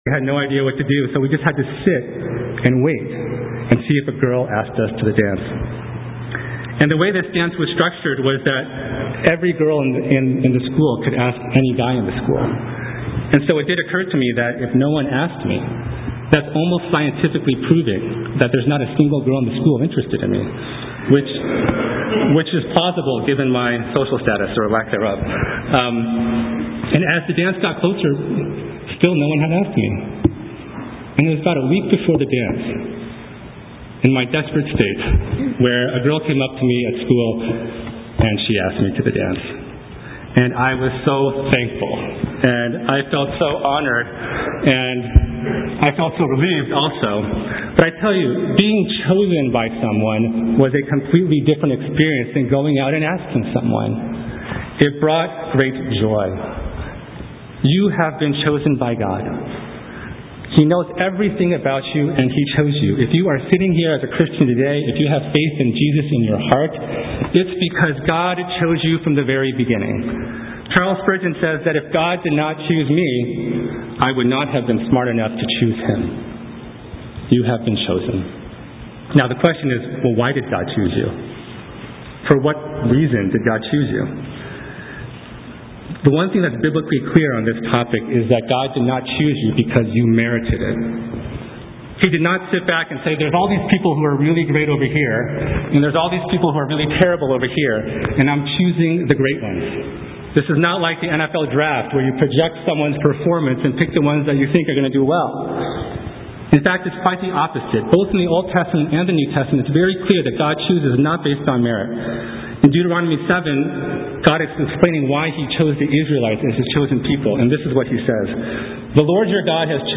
Sermons - Page 47 of 74 | Boston Chinese Evangelical Church